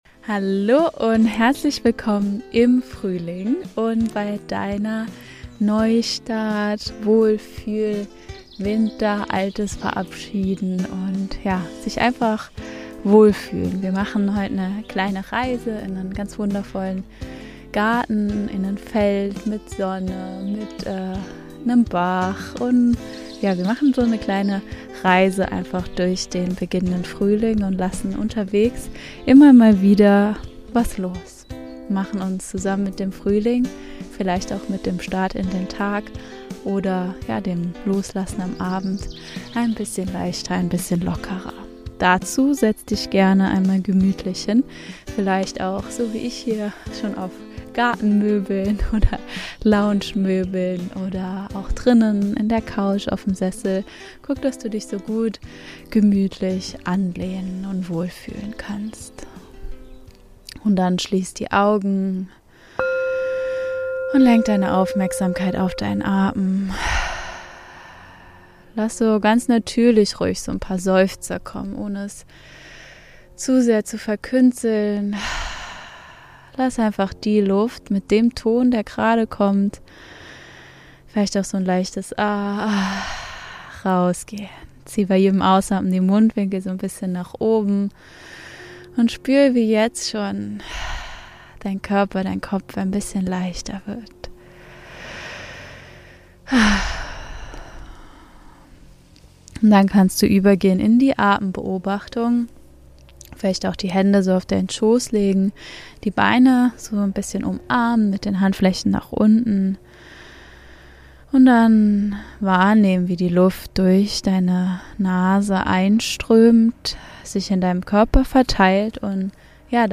In dieser geführten Meditation nimmst du Abschied von der Schwere des Winters und begrüßt den Frühling – ruhig, klar und in deinem eigenen Tempo.